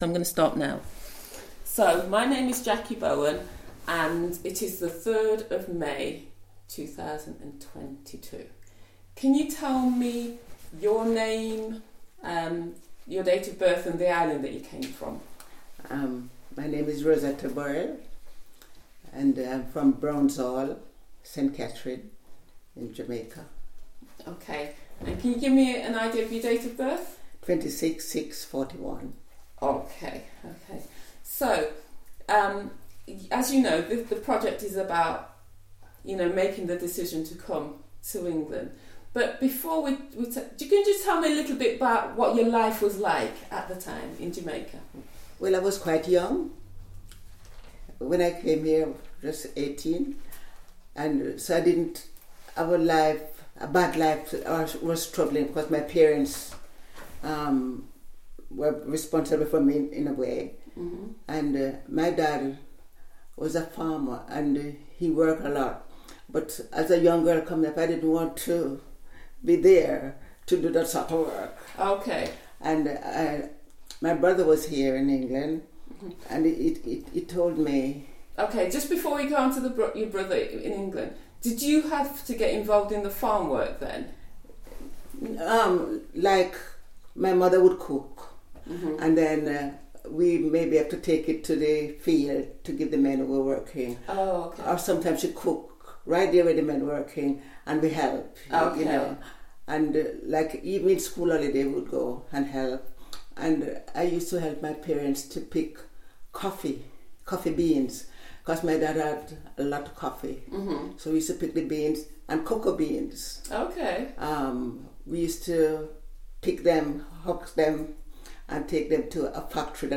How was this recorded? As part of a National Lottery Heritage fund project, local Nottingham charity Always Community interviewed over 50 members of the Windrush generation. This exciting project asked a simple question – Why did the interviewee make their Windrush Decision?